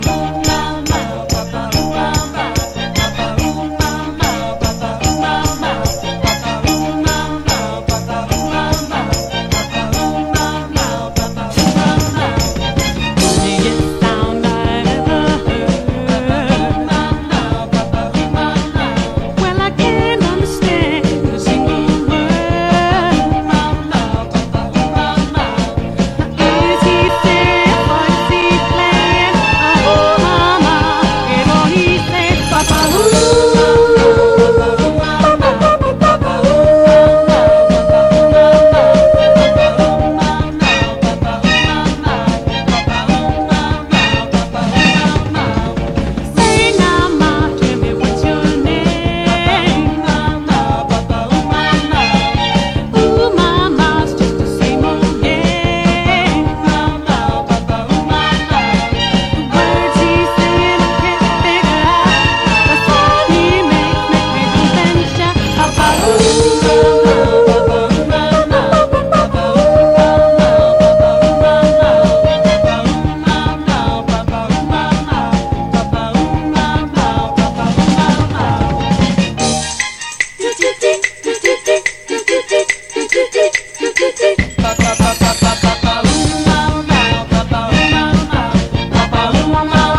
SOUL / SOUL / 60'S / DOO WOP / OLDIES